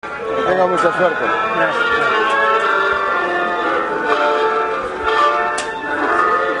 Hier ist das Grammophon zwar nicht zu sehen, aber in 5 Folgen zu hören.